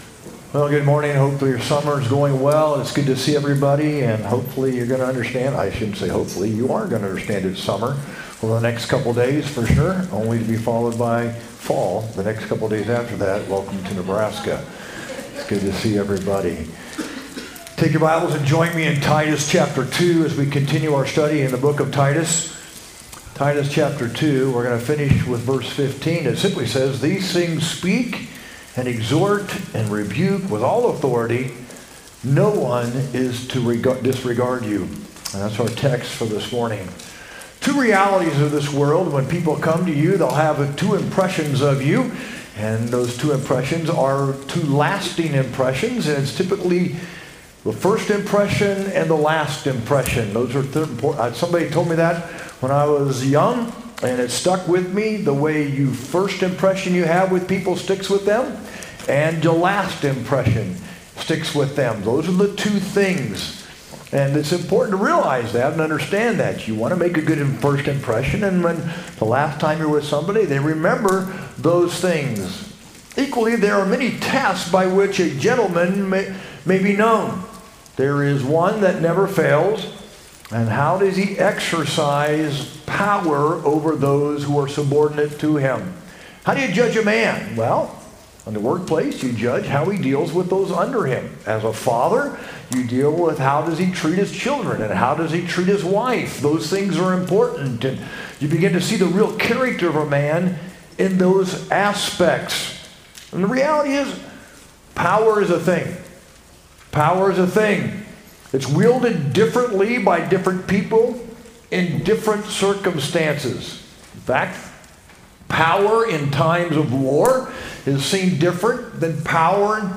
sermon-7-27-25.mp3